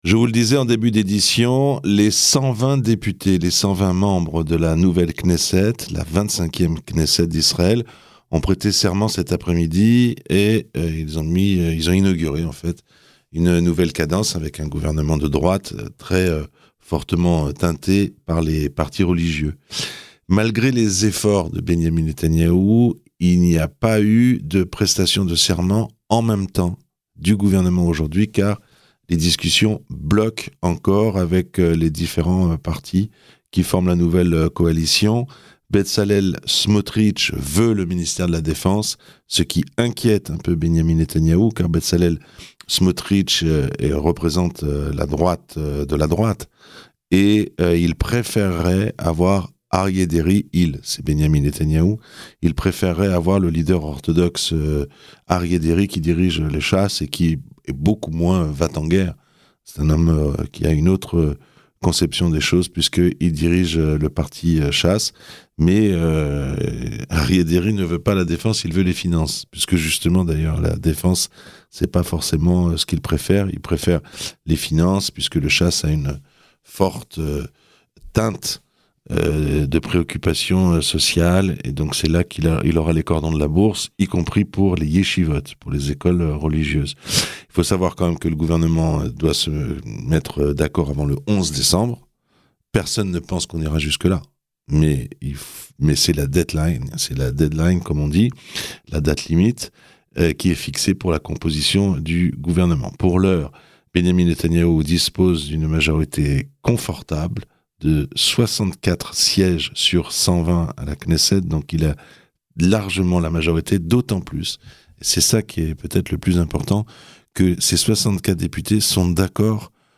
Ecoutez le discours de Micky Levy , président de la Knesset à l'occasion de l'intronisation de la nouvelle majorité.